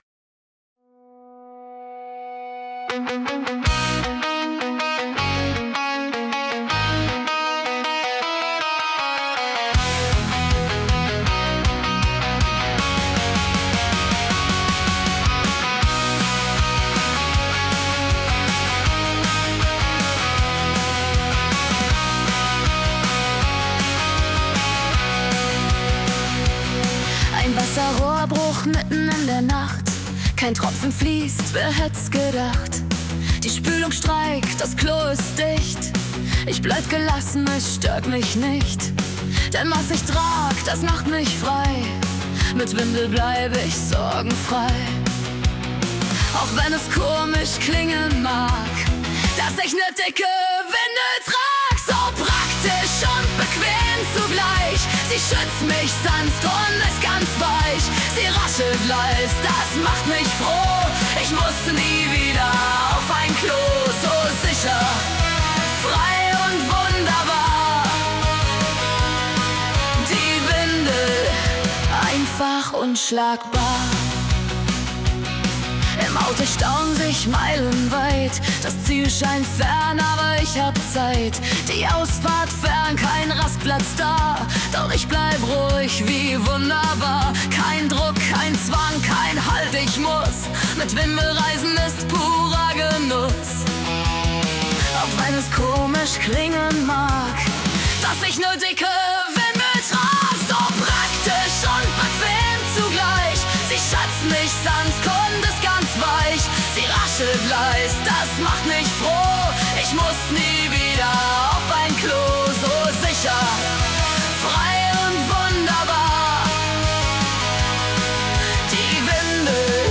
ein humorvoller Pop-Rock-Song